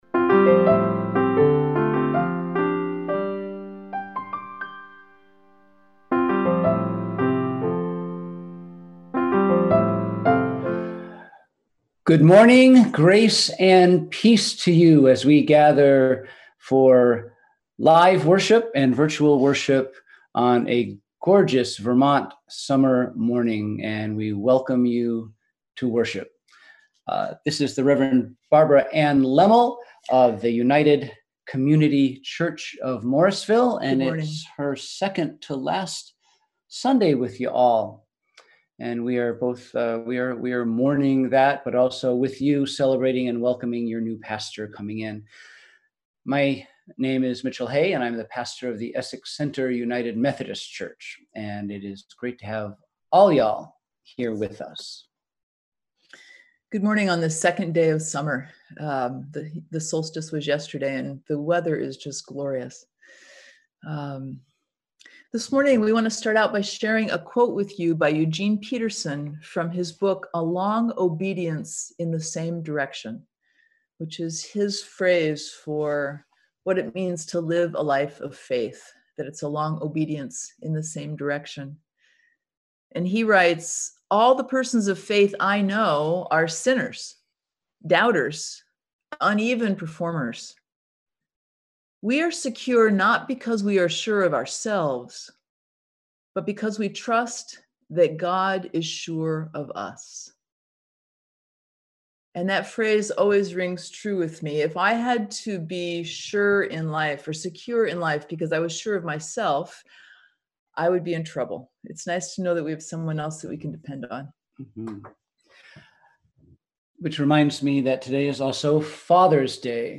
We held virtual worship this week!